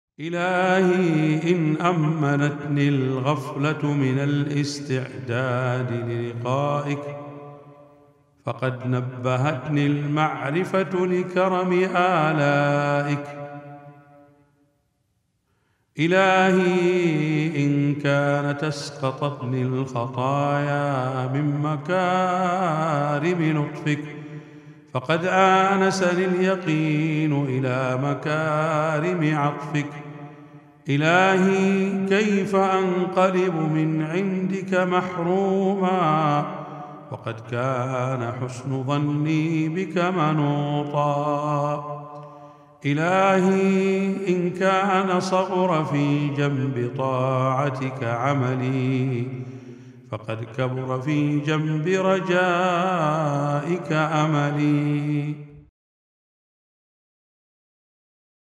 مناجاة خاشعة تظهر تذلل العبد بين يدي ربه، يعترف فيها بتقصيره وغفلته مع تعلقه برحمة الله وكرمه ويقينه بحسن ظنه به. النص يعبر عن جوهر العلاقة بين العبد وربه المبنية على الرجاء مع الاعتراف بالتقصير.